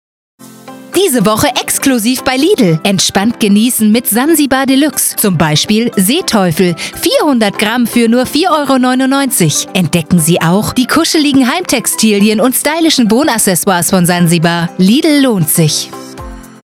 Promotions
ContraltoHaute